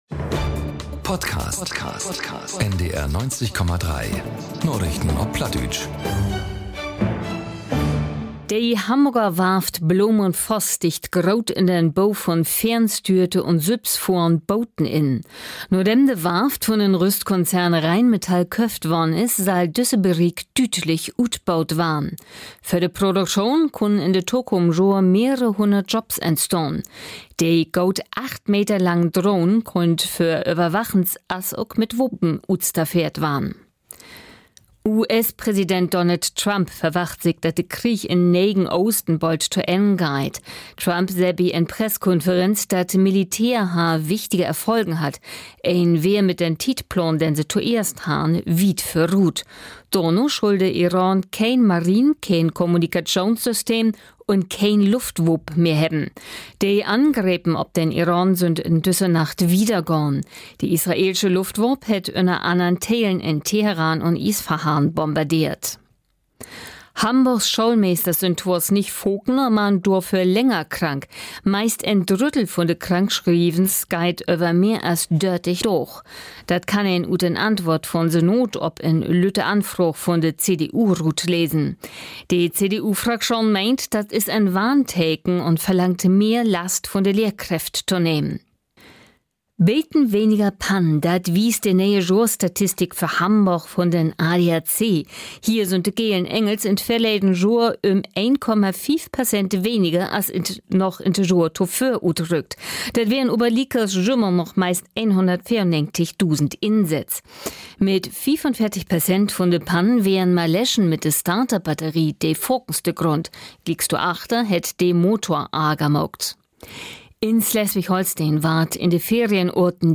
aktuellen Nachrichten auf Plattdeutsch.